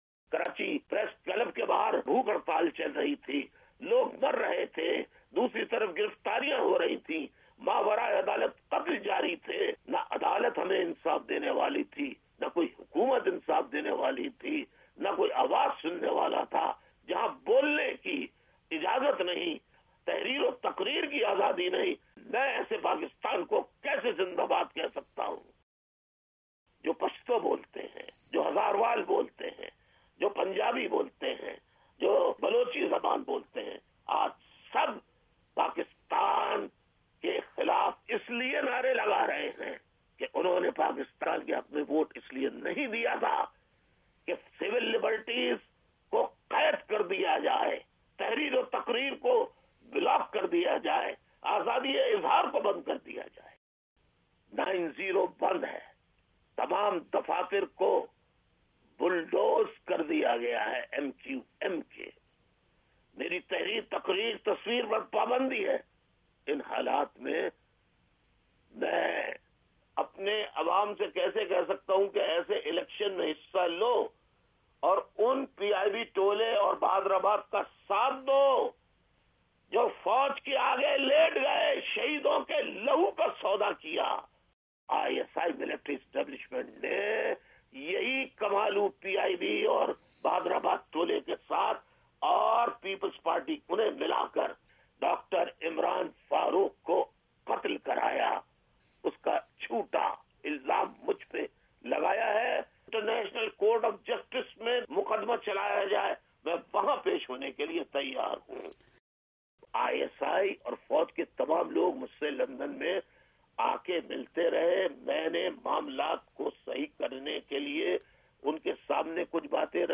الطاف حسین کا آڈیو انٹرویو 7 مئی 2018